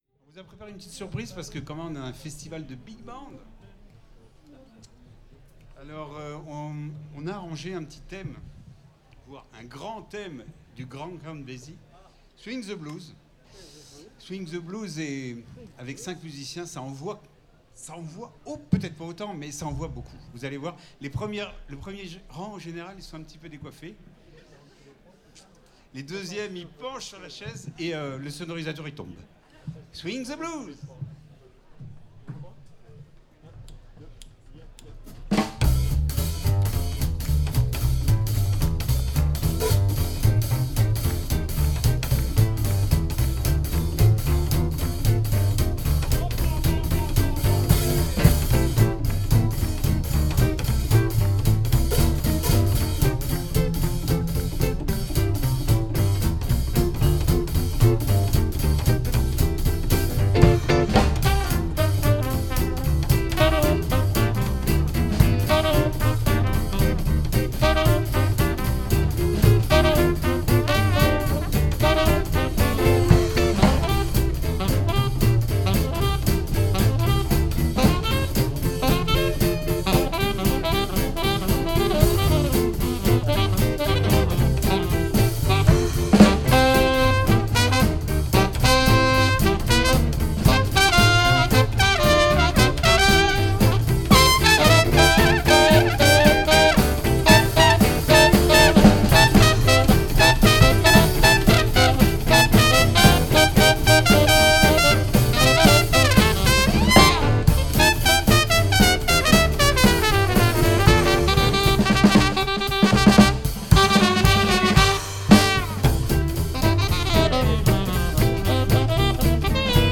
Saxophone ténor, clarinette